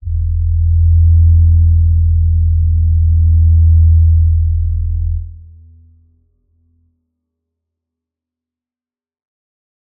G_Crystal-E2-mf.wav